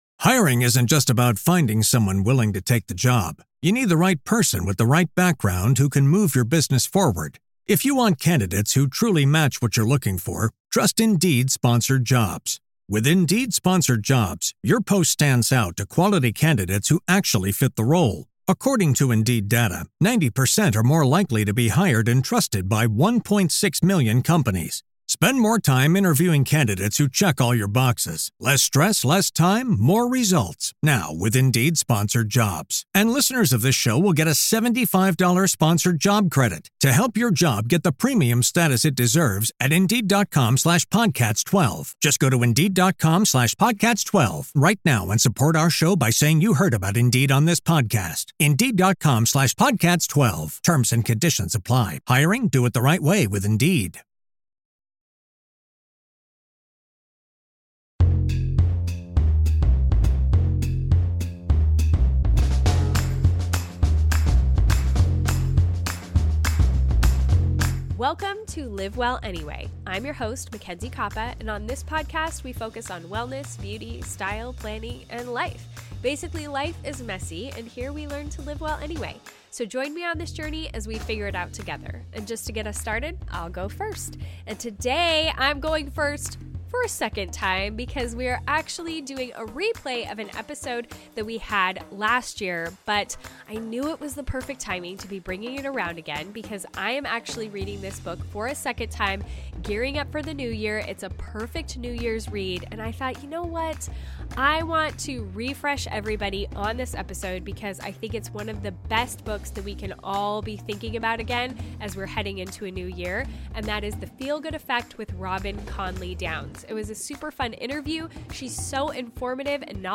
Today on Live Well Anyway, we have a reprise of one of our favorite conversations from last year!